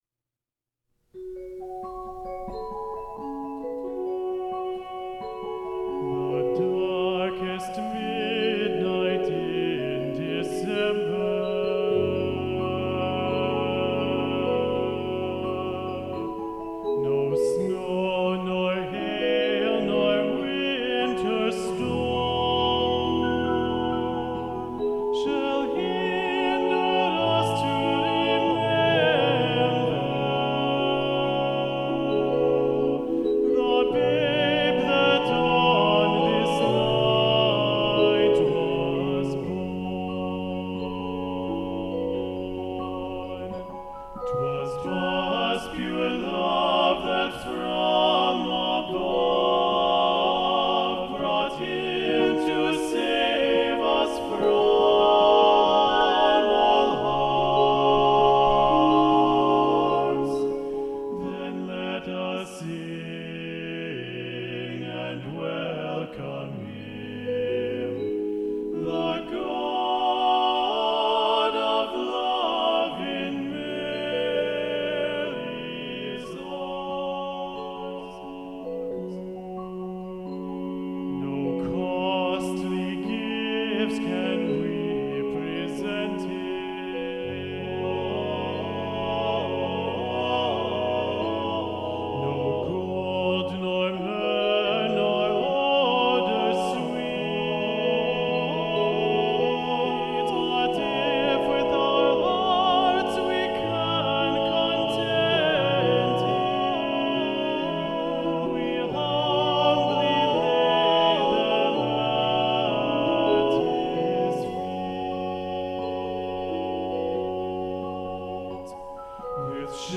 Voicing: TTBB divisi